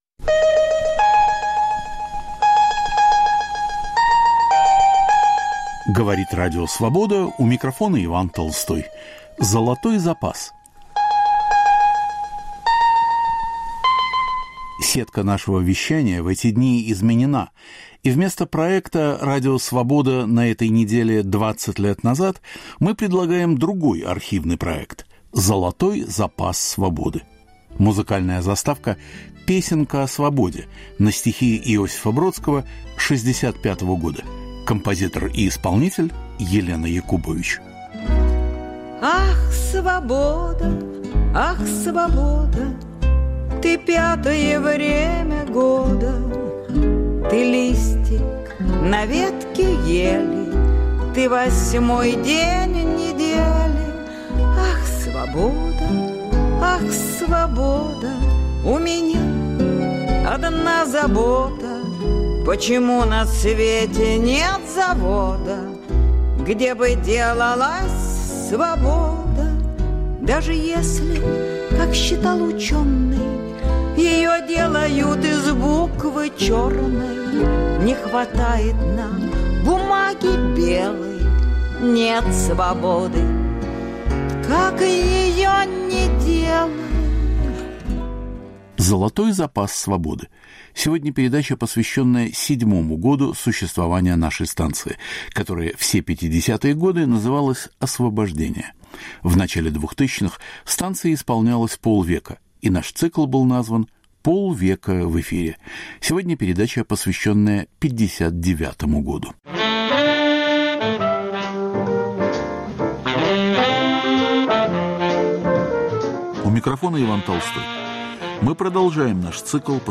Цикл передач к 50-летию Радио Свобода. Год 1959: что сохранилось в архиве?